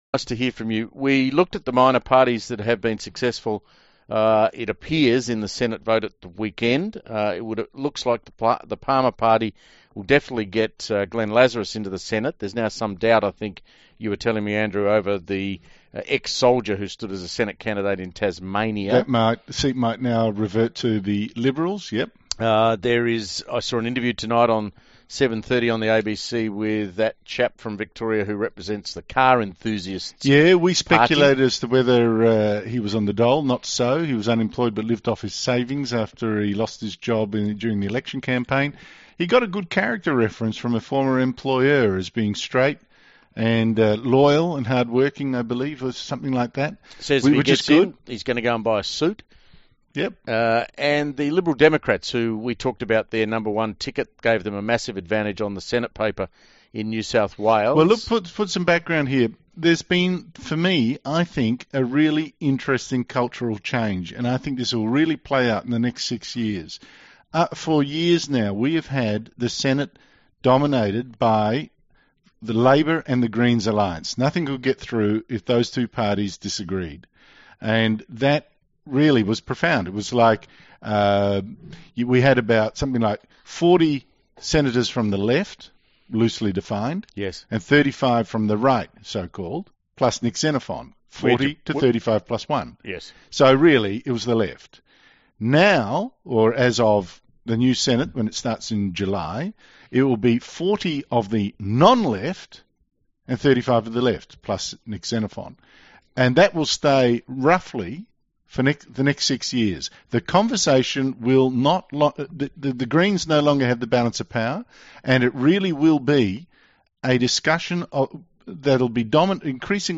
He explained the LDP’s policies in this radio interview last night with Andrew Bolt and Steve Price.
davidleyonhjelminterview.mp3